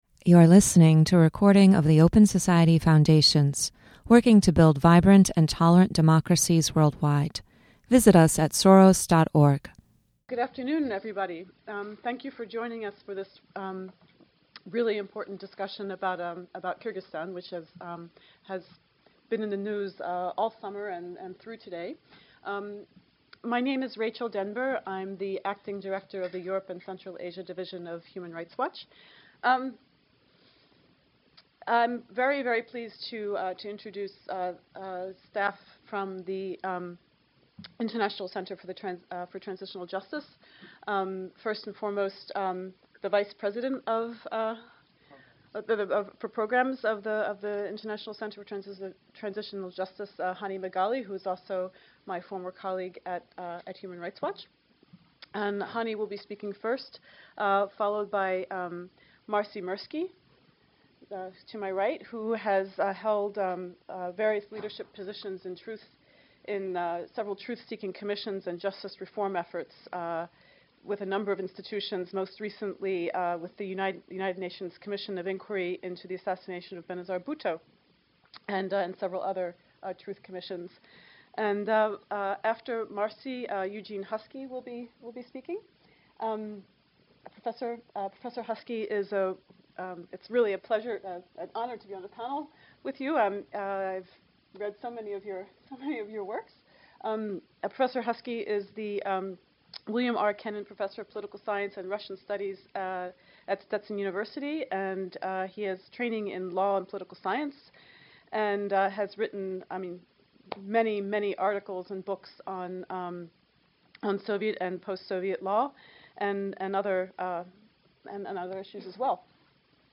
Speakers at this Open Society forum present the results of research conducted to assess how transitional justice methods could help achieve accountability for past human rights abuses in Kyrgyzstan.